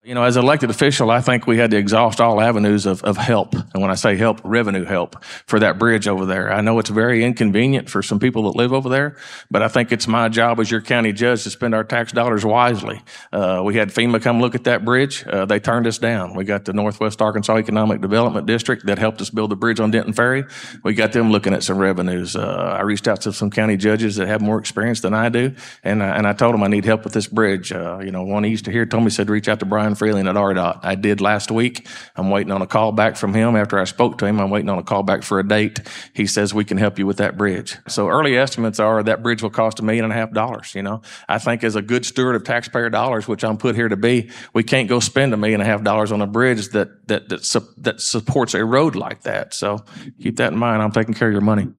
The public forum held at the Food Bank of North Central Arkansas saw County Judge Kevin Litty face challenger Eric Payne who currently serves as Justice of the Peace for District 11.